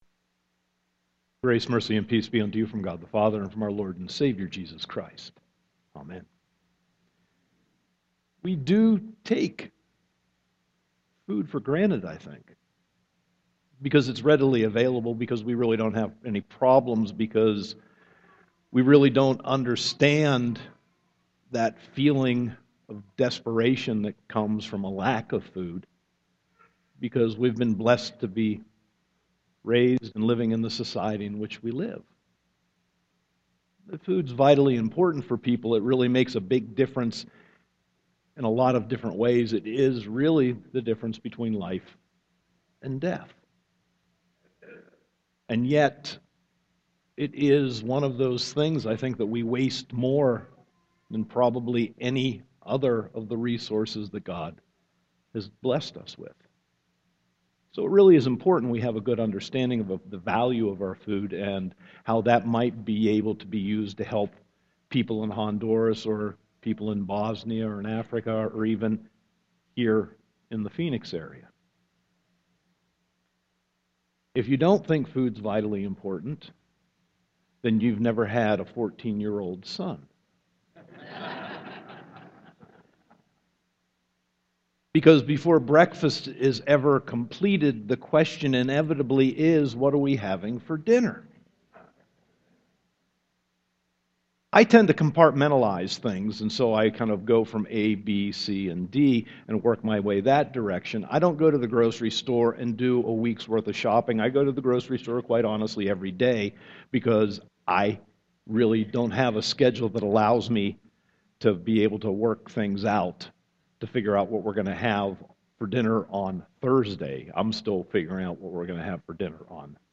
Sermon 5.4.2014